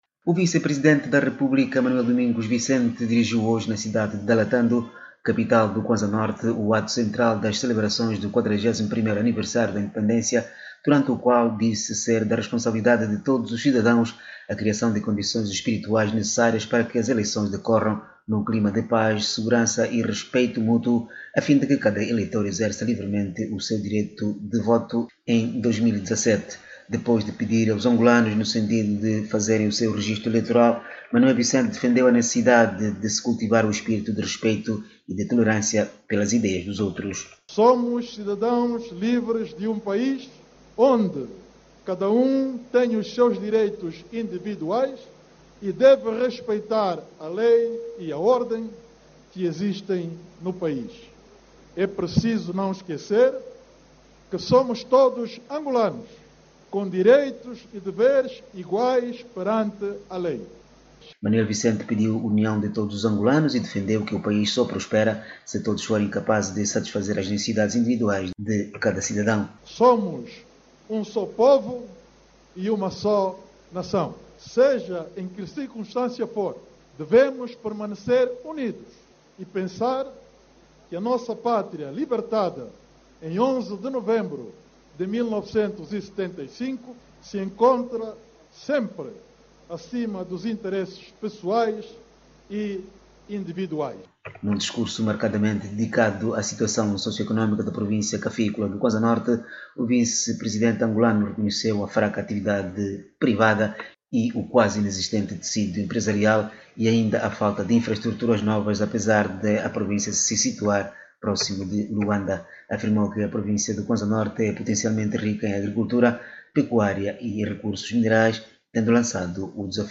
~Independencia angolana em debate - 2:47